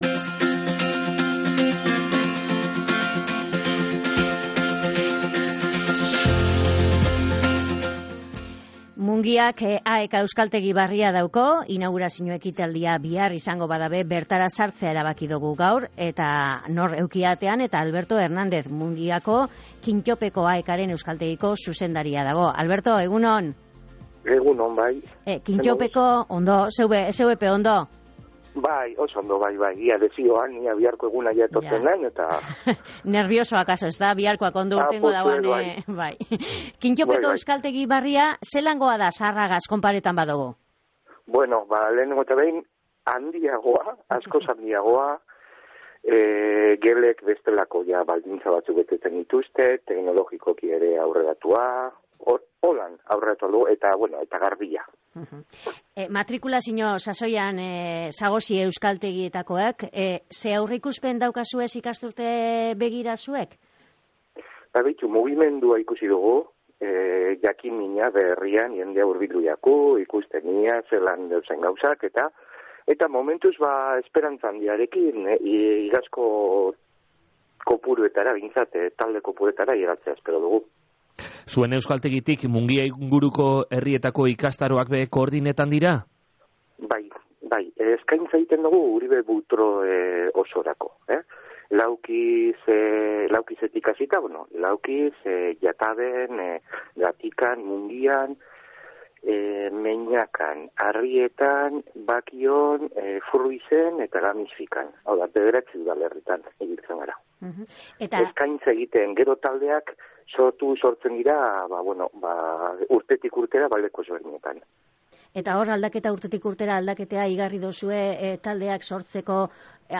Euskaltegiko eskaintza Uribe Butroe osora zabaltzen da.